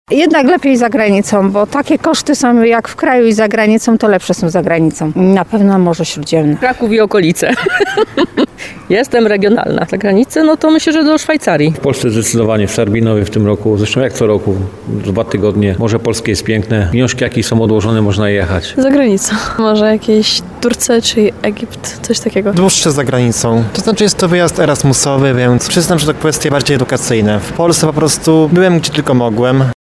Zapytaliśmy mieszkańców Tarnowa co preferują, czy krótki urlop w kraju czy dłuższy za granicą?
8wakacje-sonda.mp3